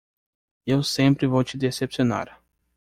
Uitgespreek as (IPA) /de.se.pi.si.oˈna(ʁ)/